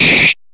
Slash1.mp3